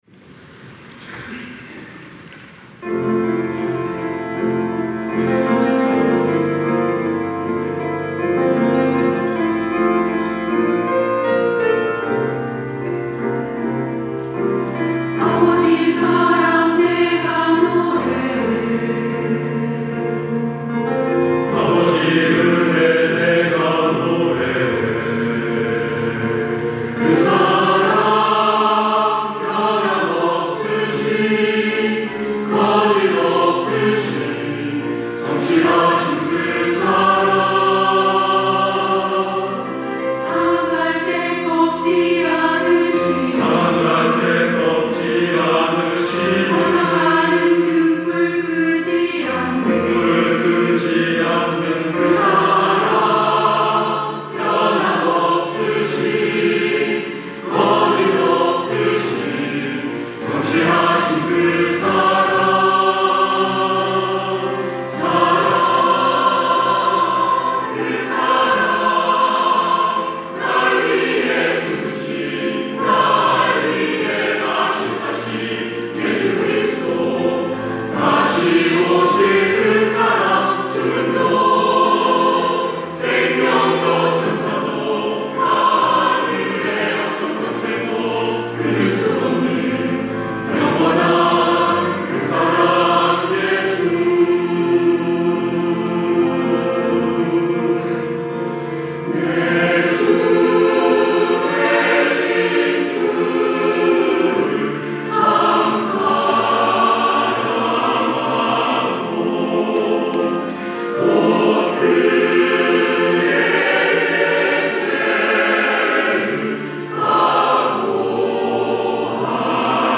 할렐루야(주일2부) - 그 사랑
찬양대